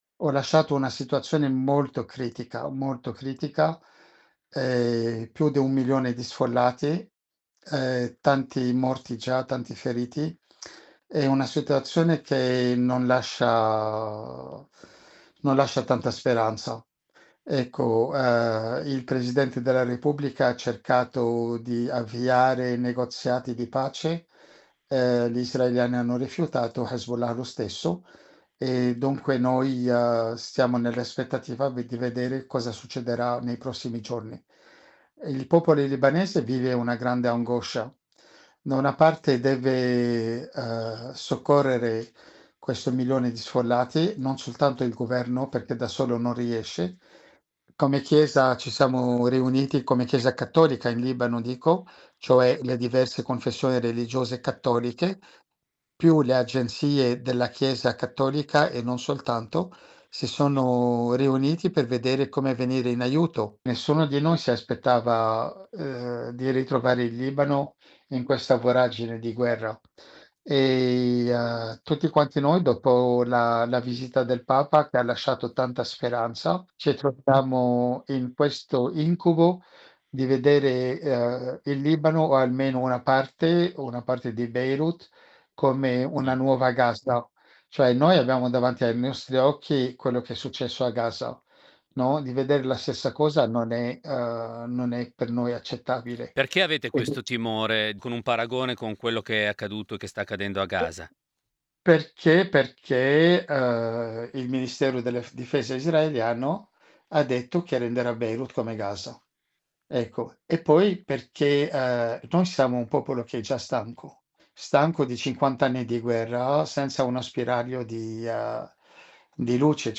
Lo raggiungiamo in occasione della sua partecipazione a Milano alla fiera “Fa' la cosa giusta”.